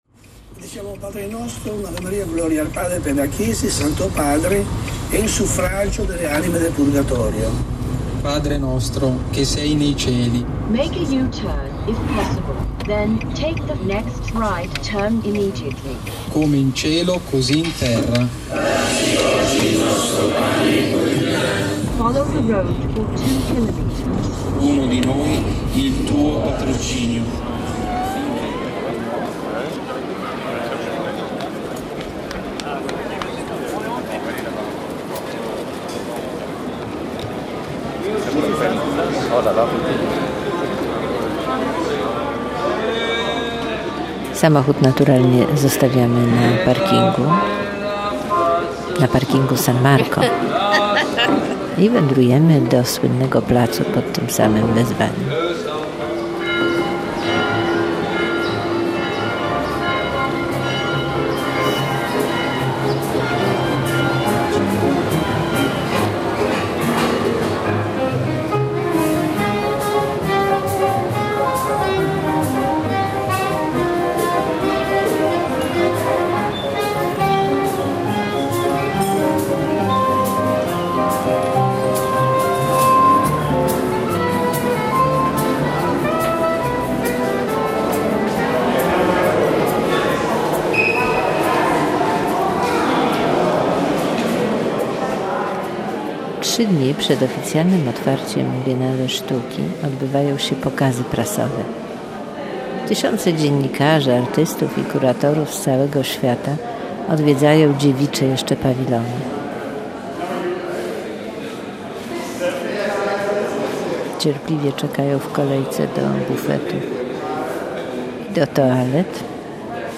Reportaż jest dźwiękową impresją z 56. Biennale Sztuki w Wenecji.